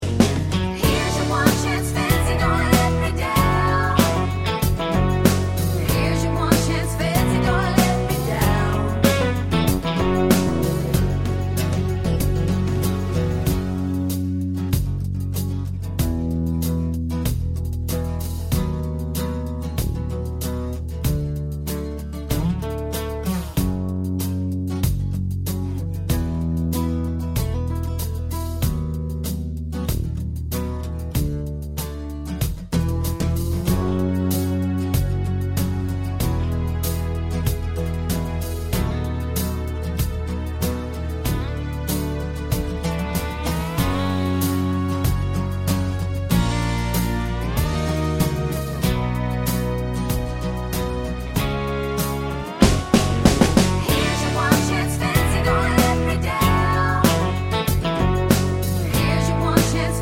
no Backing Vocals Country (Female) 4:39 Buy £1.50